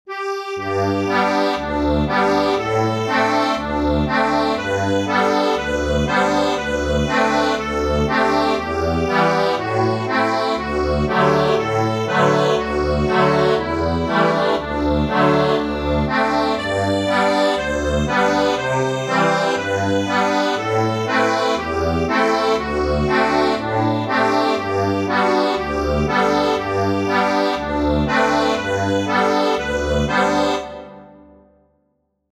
Instrument:  Accordion
Easy accordion arrangement plus lyrics.